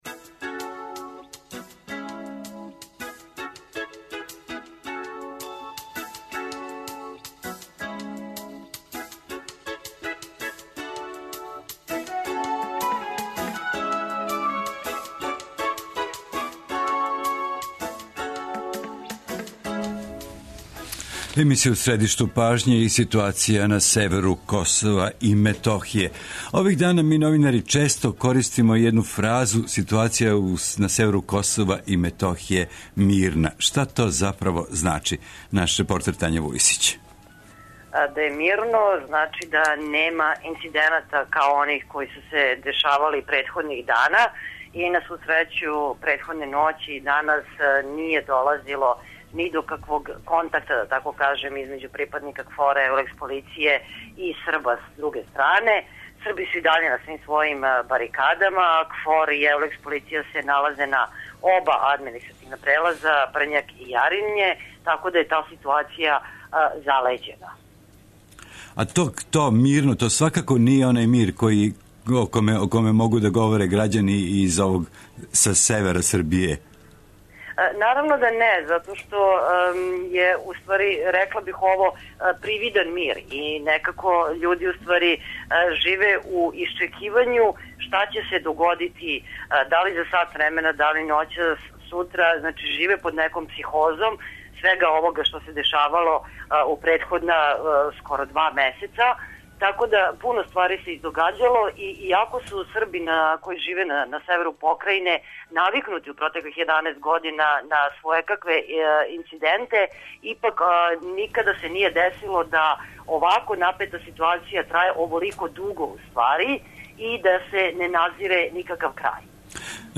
Последњи догађаји на северу Косова и Метохије биће тема емисије. Разговараћемо са репортерима који су свакоднево на лицу места и са дописницима из Брисела, који прате реаговања у седишту Европске Уније.
Очекује се да ће у време емисије о ситуацији на Косову и Метохији бити речи и на седници Скупштине Србије, одакле ће се јављати наш извештач.